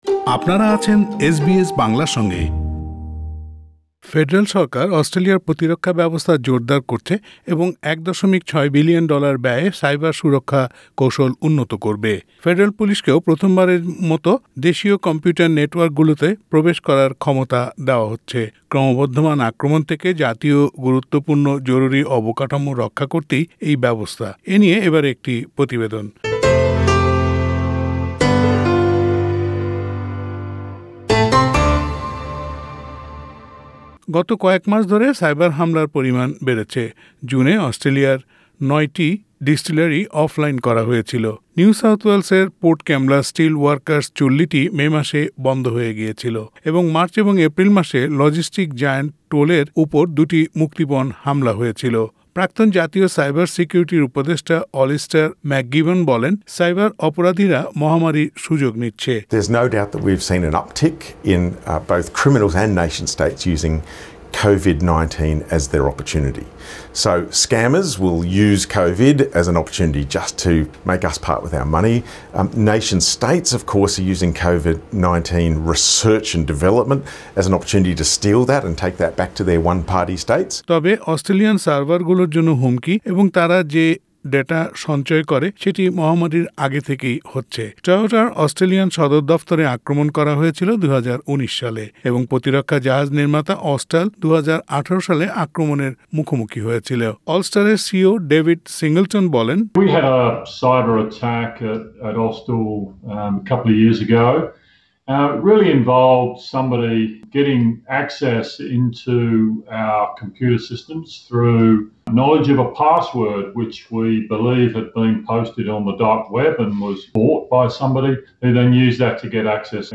উপরের প্রতিবেদনটি শুনতে অডিও প্লেয়ারের লিংকটিতে ক্লিক করুন।